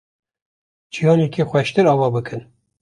Prononcé comme (IPA)
/ɑːˈvɑː/